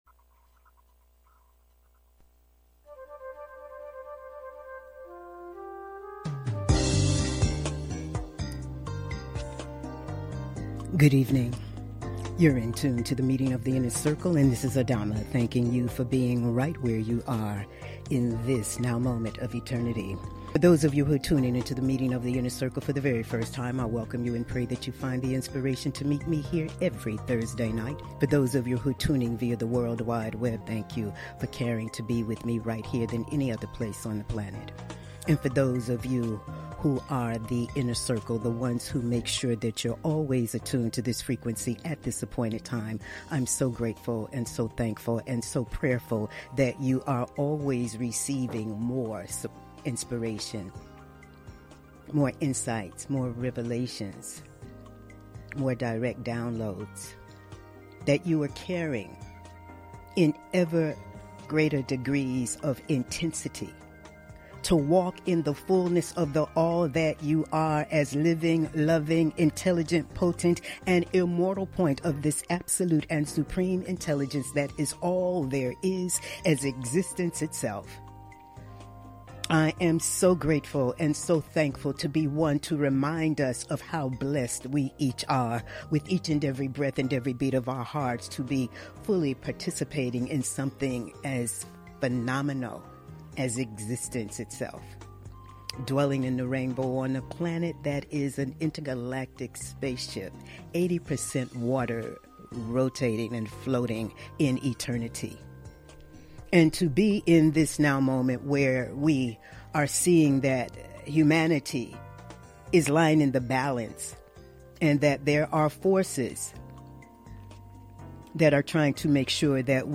Monologues